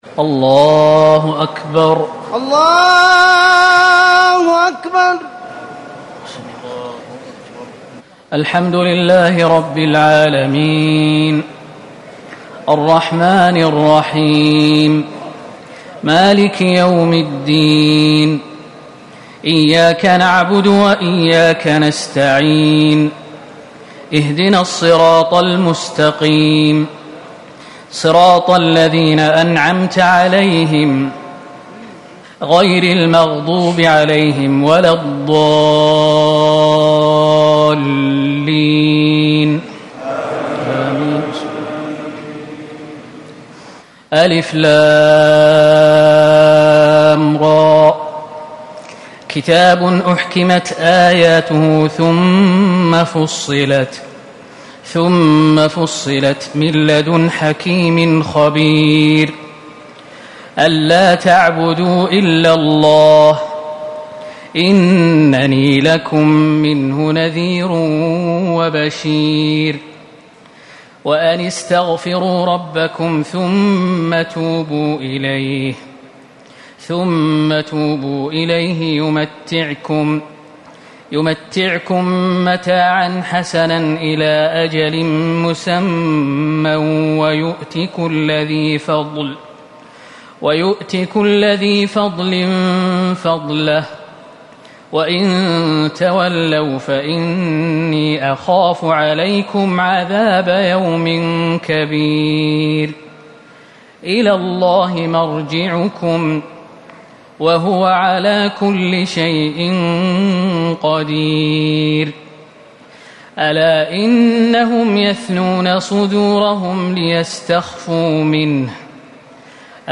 ليلة ١١ رمضان ١٤٤٠ سورة هود ١-٨٢ > تراويح الحرم النبوي عام 1440 🕌 > التراويح - تلاوات الحرمين